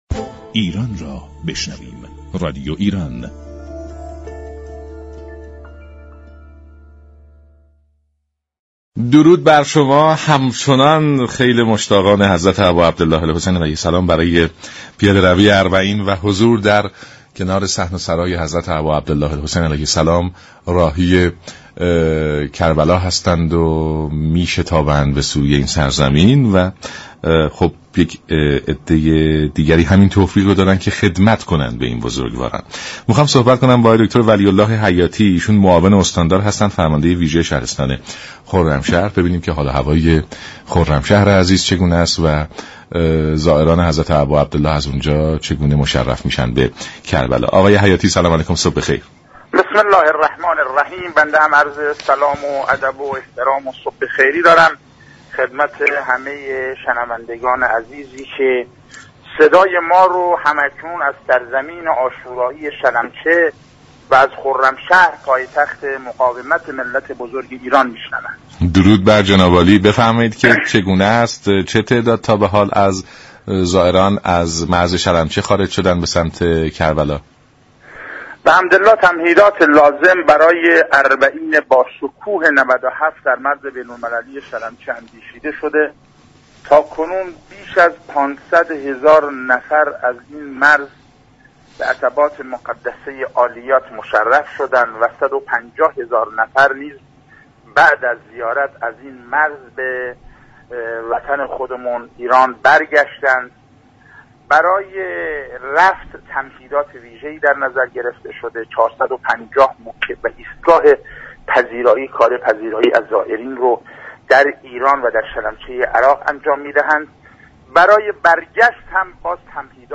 معاون استاندار و فرماندار ویژه خرمشهر در گفت و گو با رادیو ایران گفت: در مرز شلمچه هیچگونه مشكلی وجود ندارد و ترافیك روان است